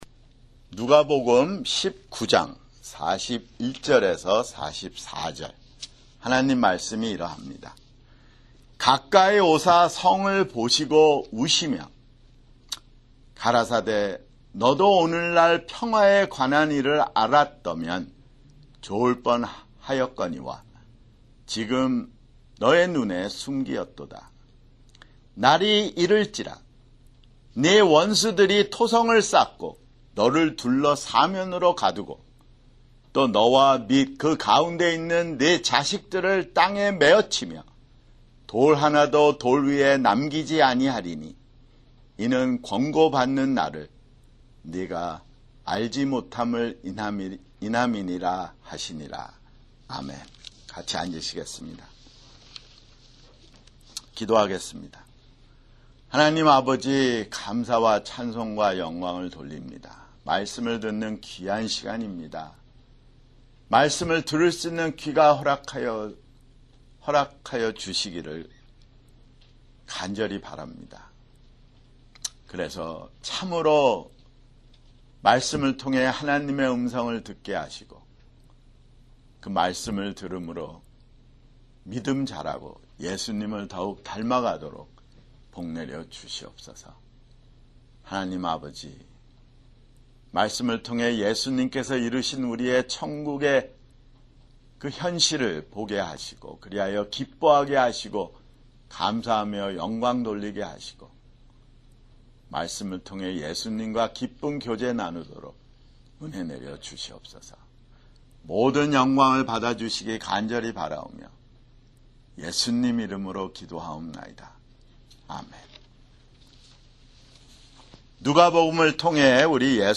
[주일설교] 누가복음 (128)